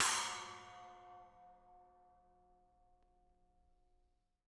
R_B Splash B 01 - Room.wav